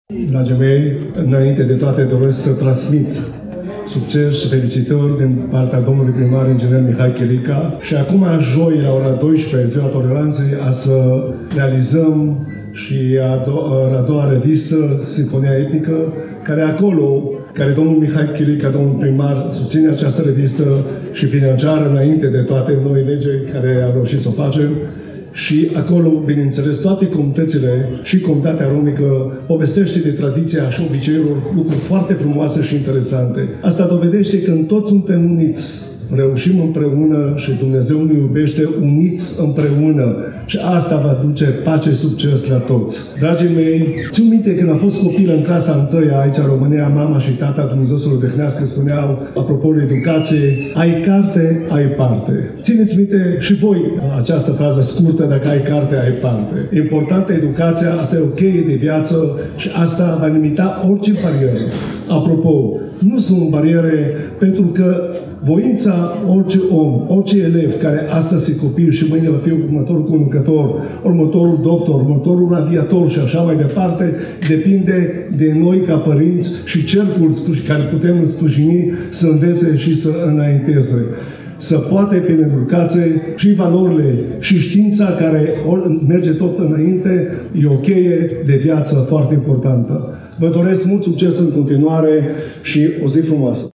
La conferința – Incluziunea romilor – bariere, oportunități și perspective, organizată în cadrul proiectului Mișcarea civică pentru echitate și incluziune, eveniment care s-a desfășurat în ziua de marți, 14 noiembrie 2023, începând cu ora 14, în Sala Mare de Spectacole „Radu Beligan” a Ateneului Național din Iași, am asistat și la discursul